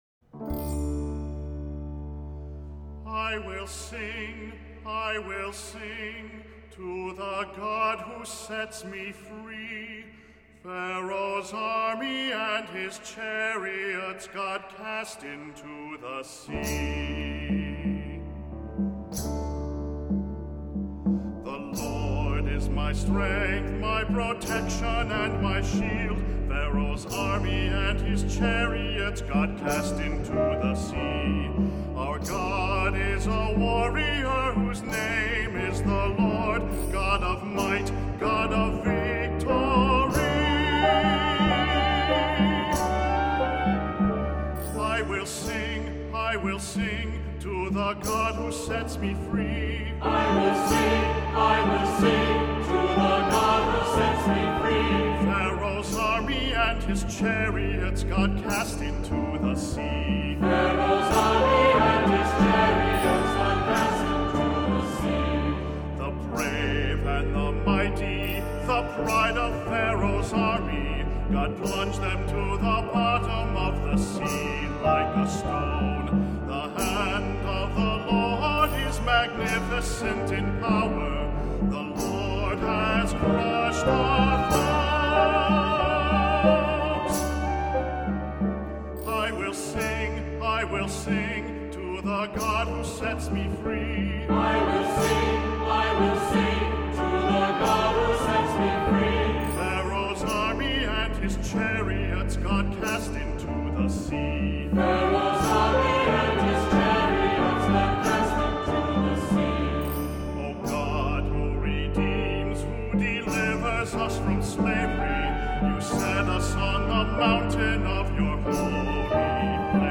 Accompaniment:      Keyboard, C Instrument I;C Instrument II
Music Category:      Christian